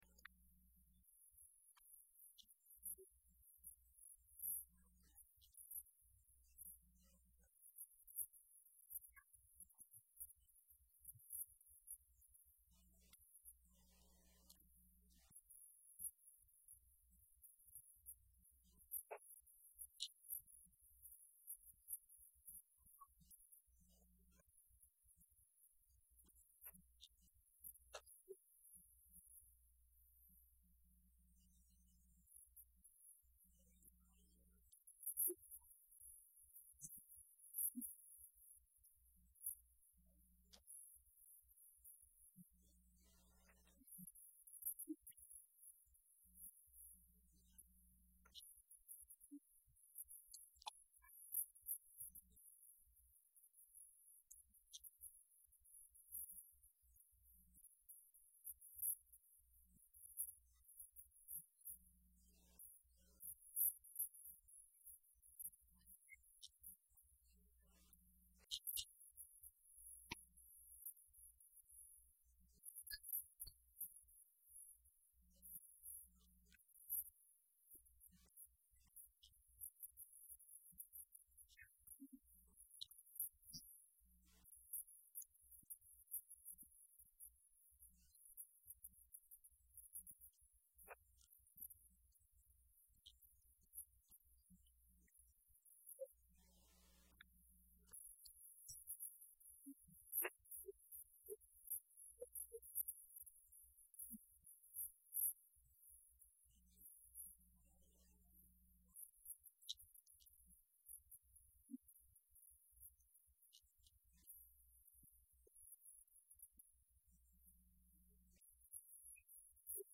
When Mary Magdalene saw Jesus after the Resurrection, she knew that she was fully alive, with good news to tell others! Message from John chapter 20.